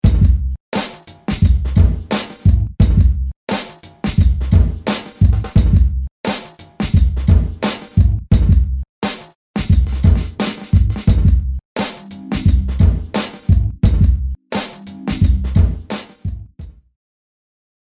hipHopBeat
hipHopBeat.mp3